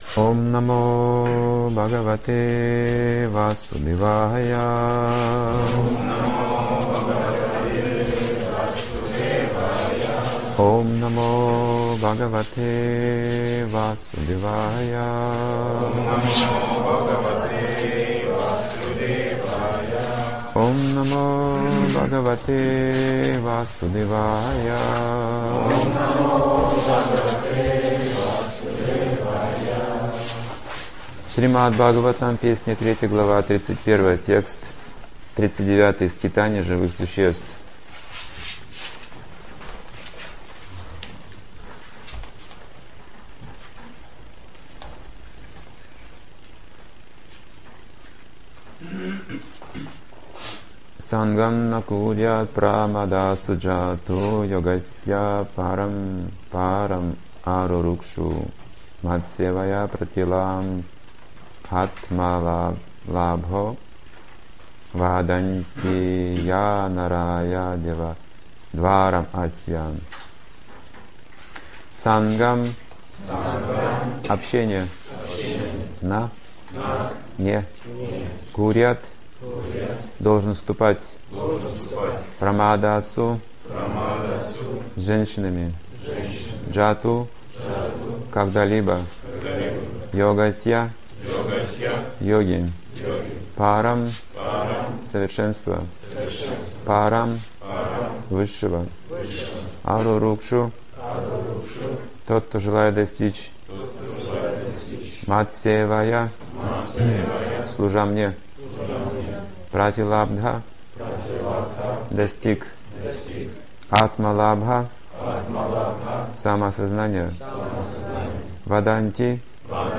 Темы, затронутые в лекции
Санкт-Петербург